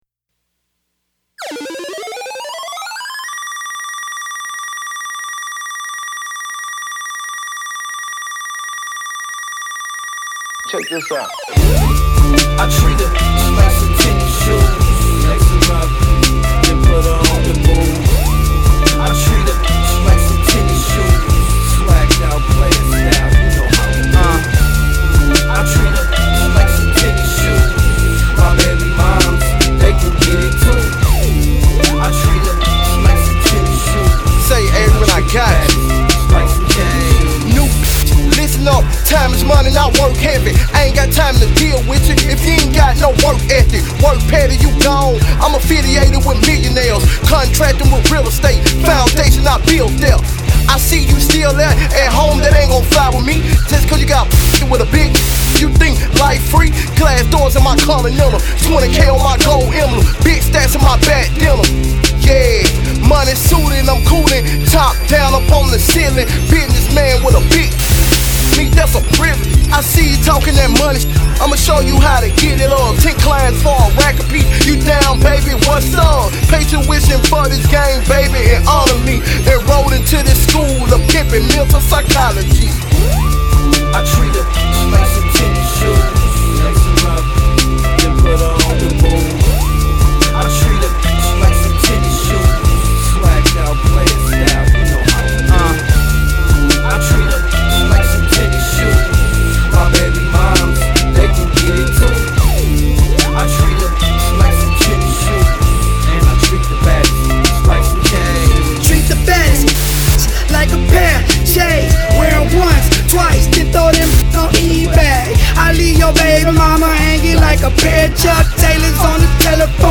(Radio Friendly).